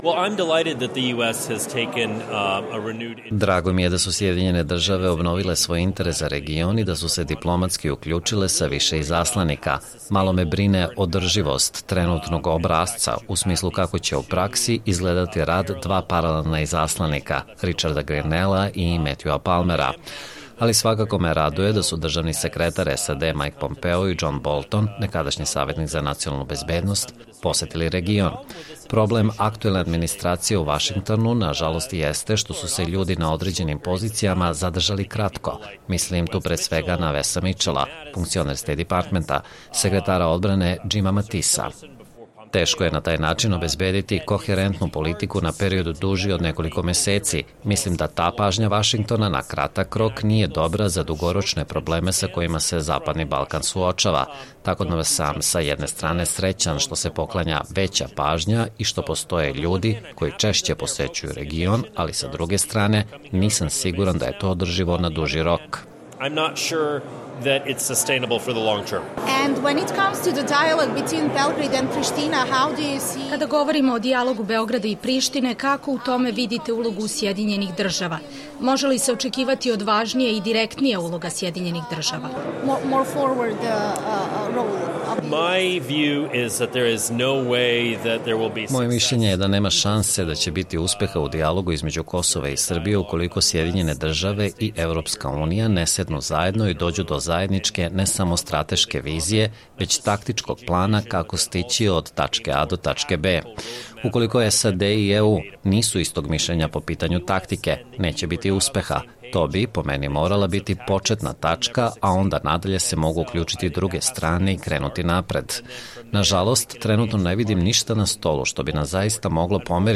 Intervju: Majkl Karpenter